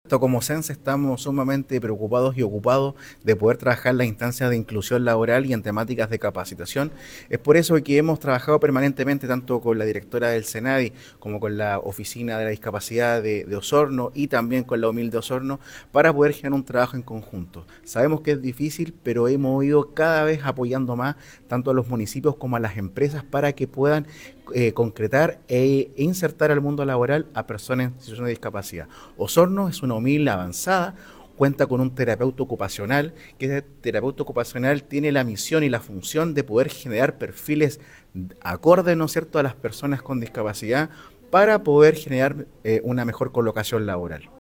El pasado lunes 14 de agosto, se realizó en dependencias del Hotel Sonesta de Osorno la Jornada de Inclusión Laboral “Avances y Desafíos” organizada por Sence Los Lagos, la dirección regional del Servicio Nacional de la Discapacidad, la Oficina Municipal de Intermediación Laboral de Osorno y la Oficina de la Discapacidad.
Desde el Servicio Nacional de Capacitación y Empleo, señalan que es muy importante continuar apoyando a los municipios y a las empresas para integrar a las personas con discapacidad al mundo laboral, como lo señaló su director regional Mauricio Toro.